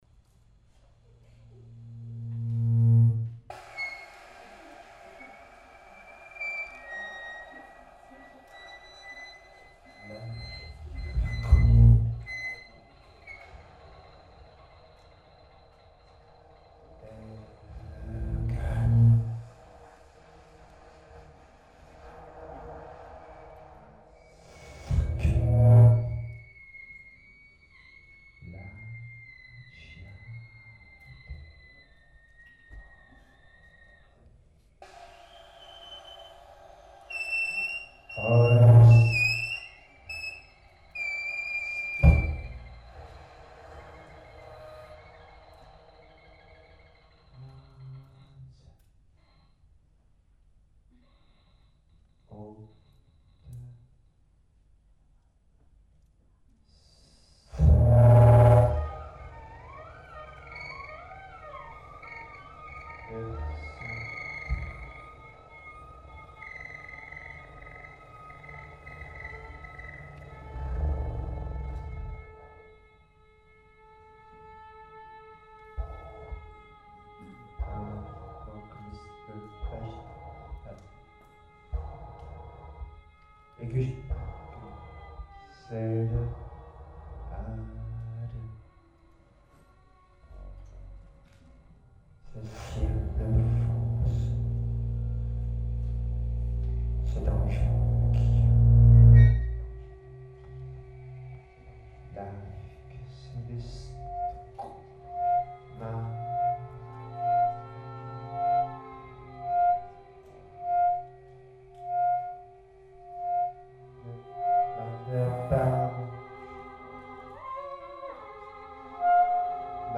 vielle à roue artisanale
saxophone
voix
musique, contemporaine, non idiomatique, improvisation